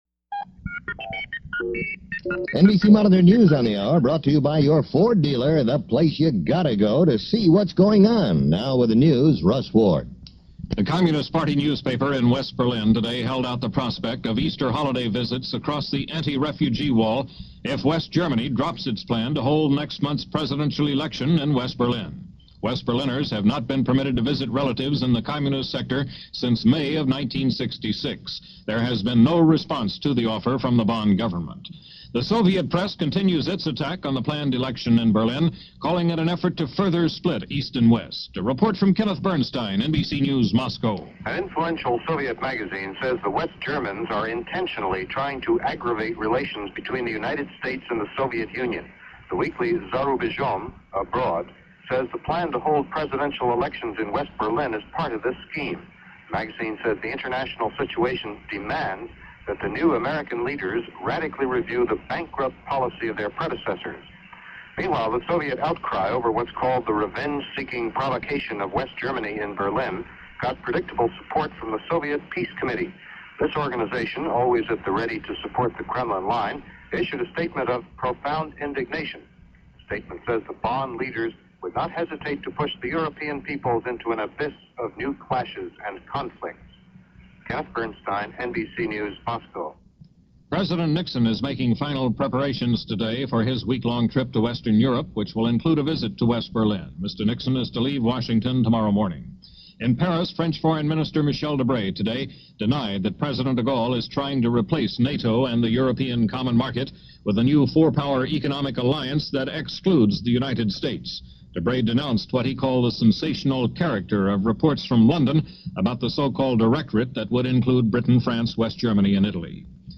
February 22, 1969 - Dread Of A Nixon Visit - End Of The Tet Holiday - George Was A Snappy Dancer - News for this day from NBC Monitor.